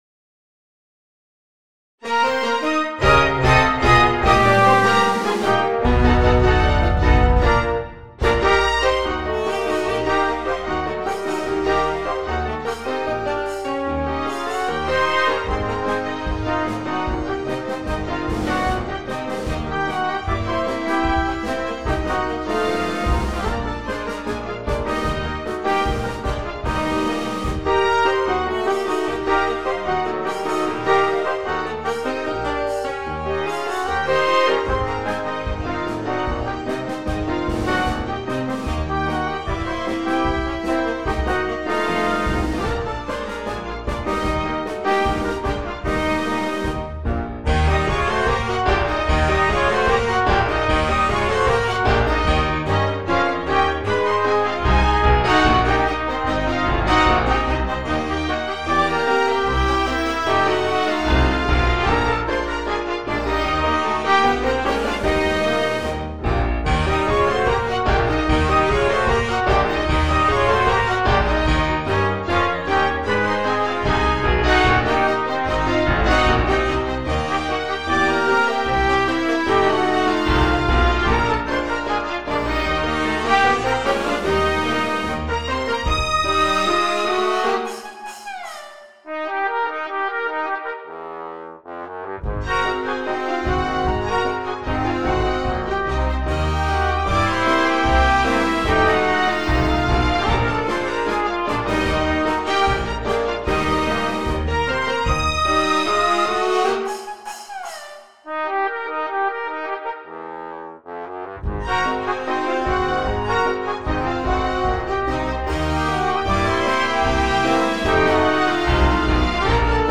Early jazz orchestra